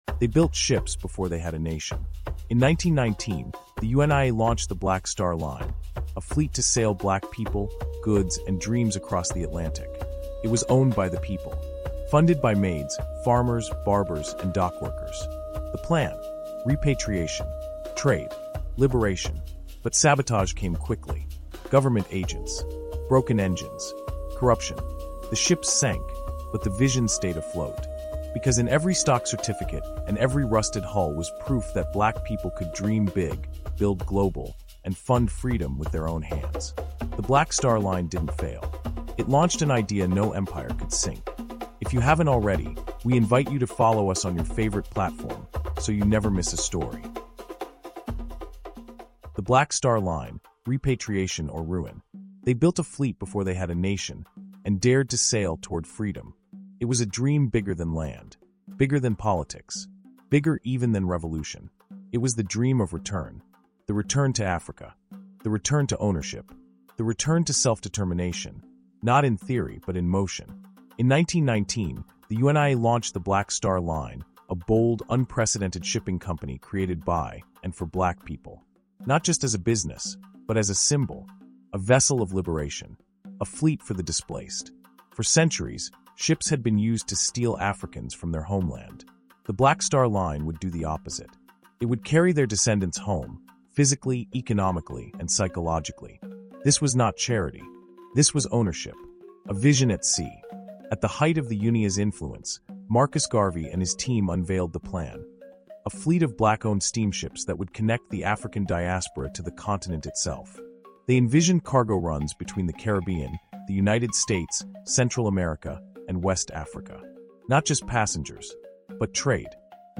UNIA: The Black Star Line | Audiobook